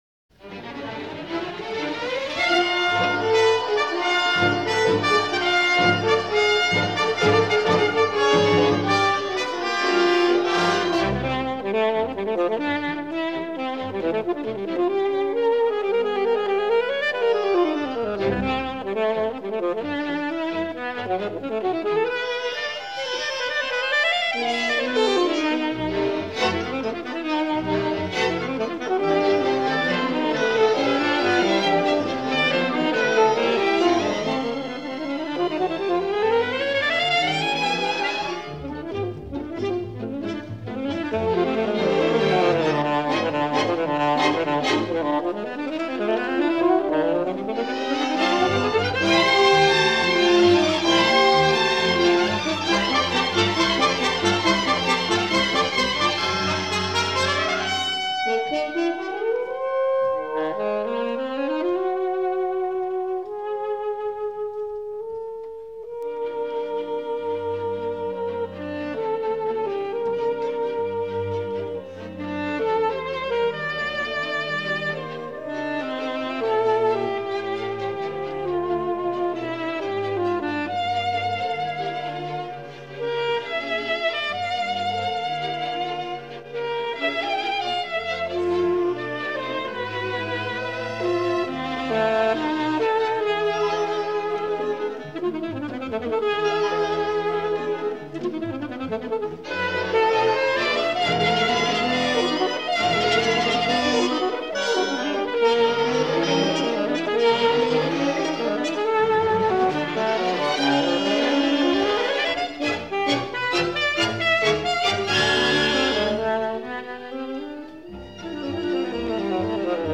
saxophone. Jacques Ibert: "Concertino da camera" for Saxophone and Eleven Instruments. I Allegro con moto. II Larghetto and animato molto.
Orchestre de la Suisse Romande.